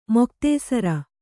♪ moktēsara